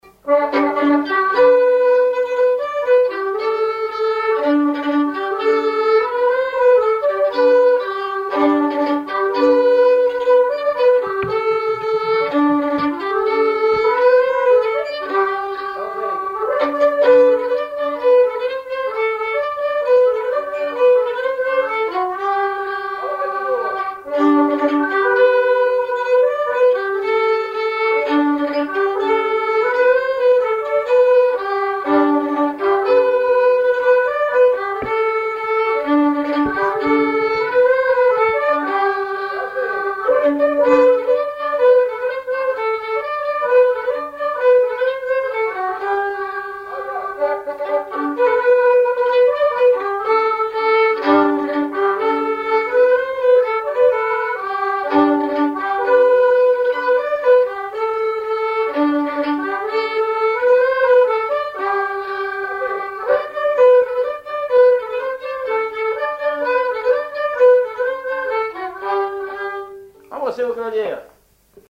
Chants brefs - A danser
danse : avant-quatre
Pièce musicale inédite